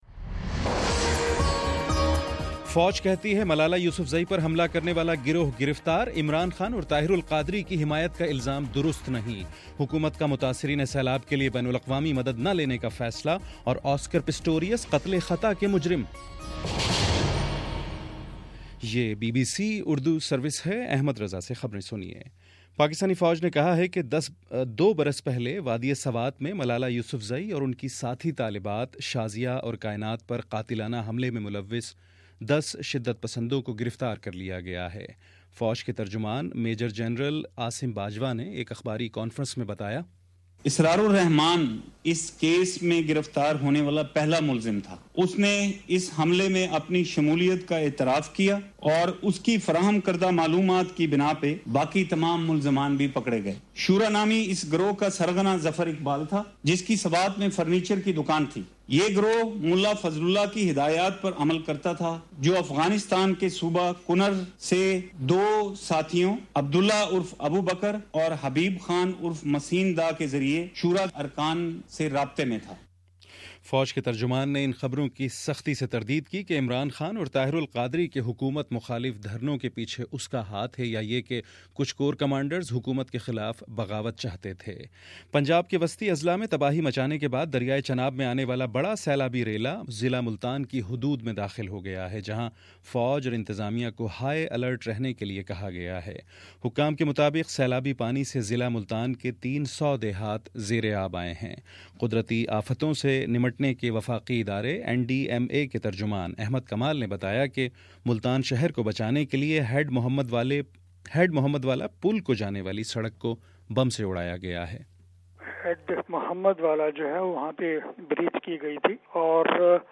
جمعہ12 ستمبر: شام چھ بجے کا نیوز بُلیٹن
دس منٹ کا نیوز بُلیٹن روزانہ پاکستانی وقت کے مطابق صبح 9 بجے، شام 6 بجے اور پھر 7 بجے۔